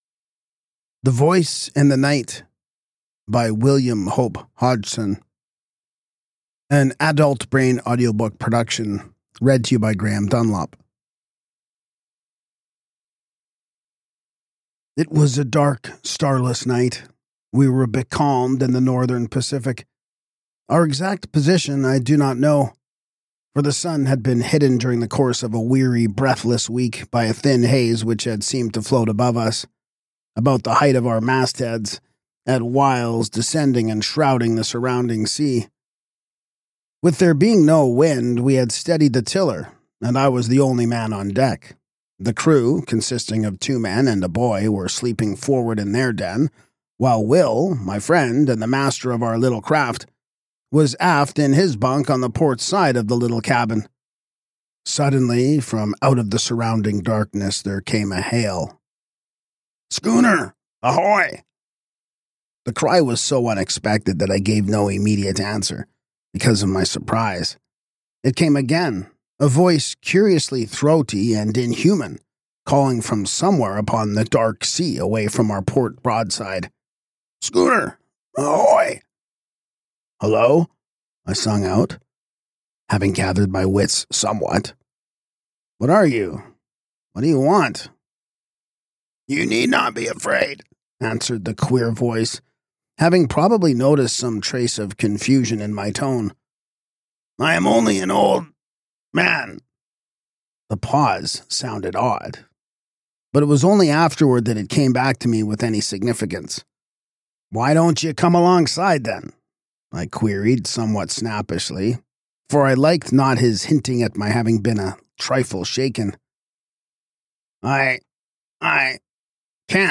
Podcast (audiobooks): Play in new window | Download